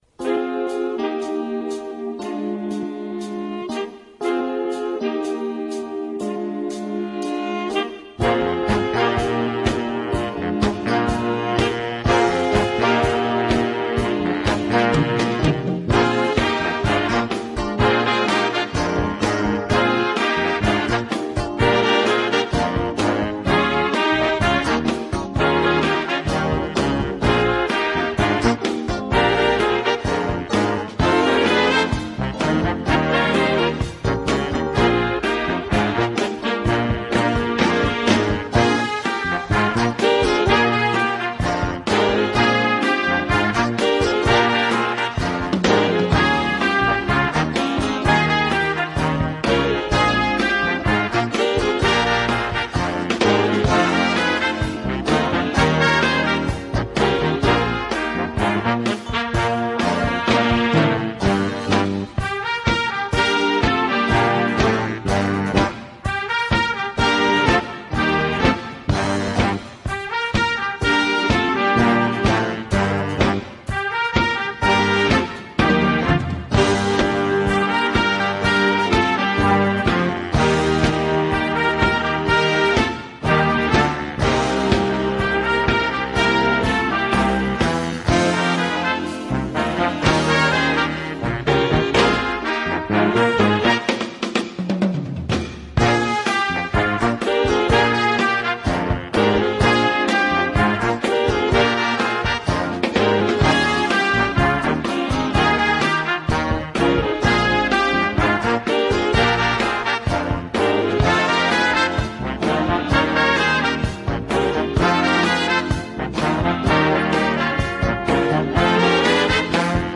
Voicing: Jazz Ensemble